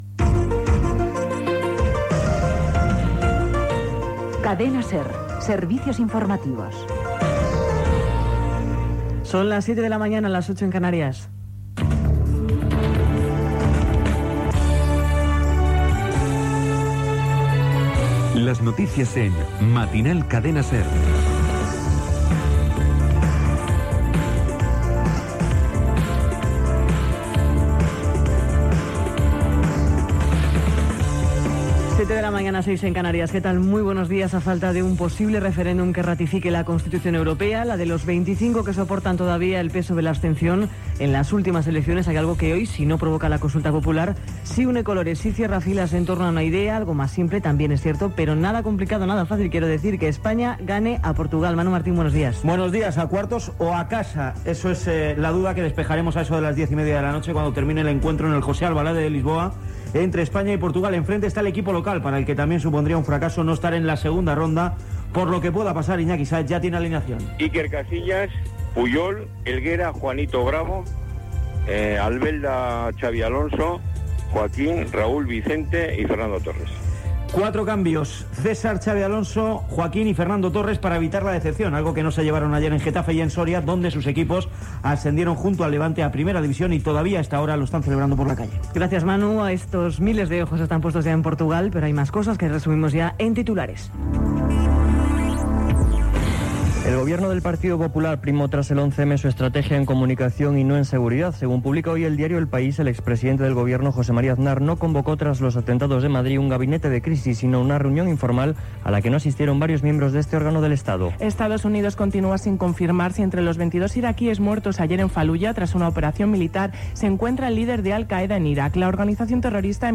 Indicatiu dels serveis informatius, hora, careta del programa, partit de futbol masculí Portugal-Espanya, titulars, publicitat.
Informatiu